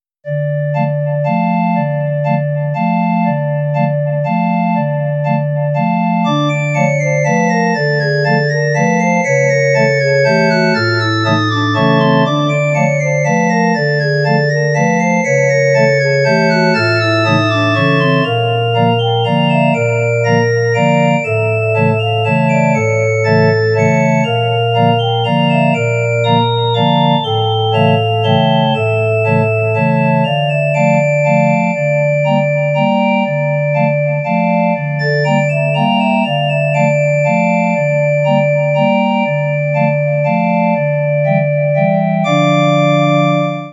悲しい雰囲気が漂うオルガンが鳴っていた！
witchcircus_sorrow.mp3